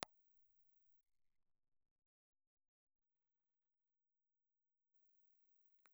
Condenser
Cardioid
Impulse Response File:
Impulse Response file of the Grundig GCM3 microphone.
Grundig_GCM3_IR.aiff
However, the output is still strong with a good signal to noise.